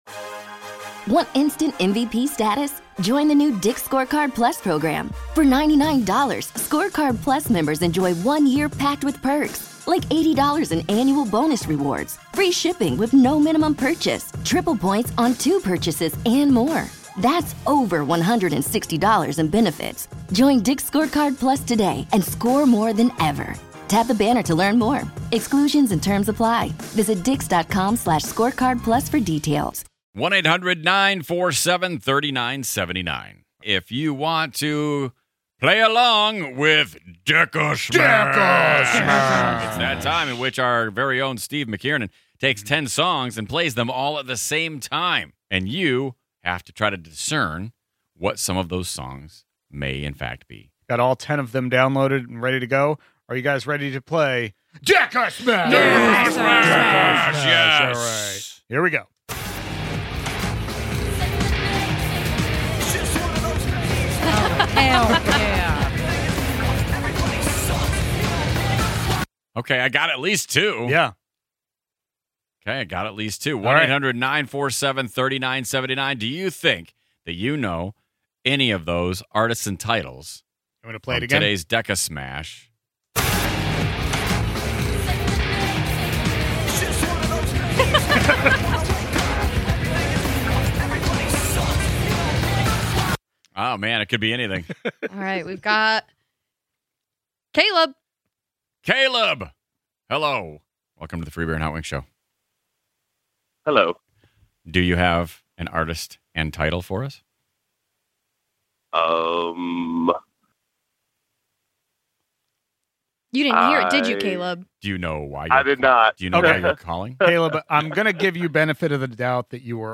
put together a list of ten songs, smashed them all together, and played them at the same time. Can you guess any of the 10 songs from DECASMASH?